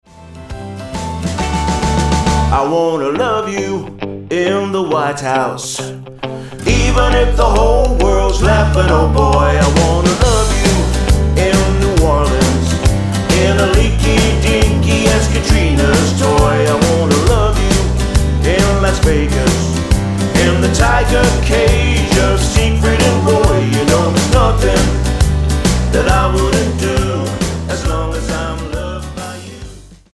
Tonart:G Multifile (kein Sofortdownload.